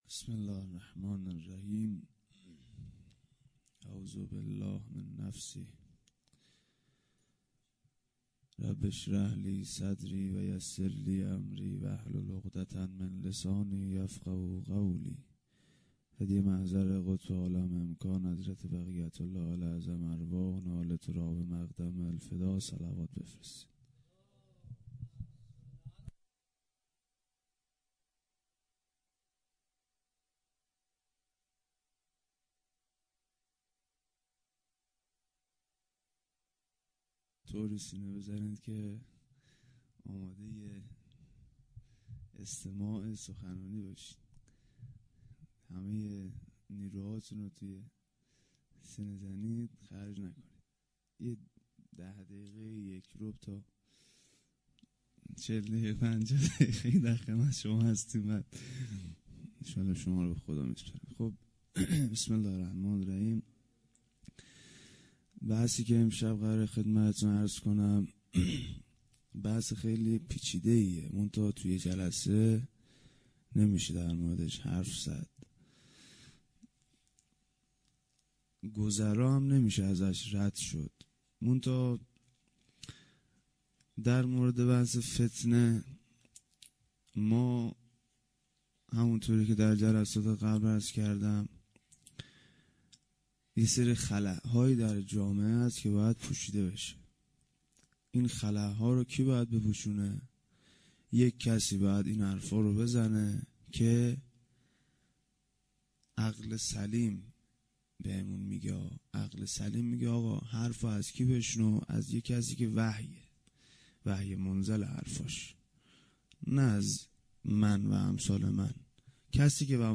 روضه هفتگی(گرامیداشت حماسه 9 دی)--جمعه 7 دیماه 97